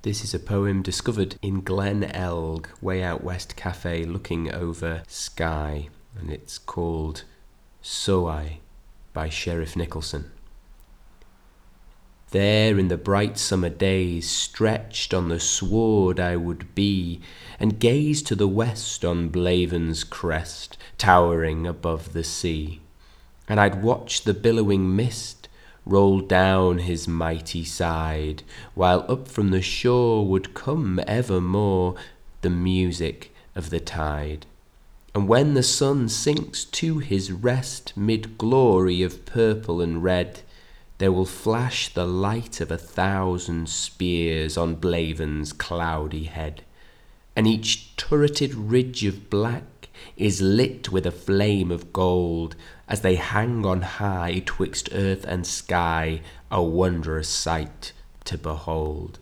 soay-by-sheriff-nicolson.wav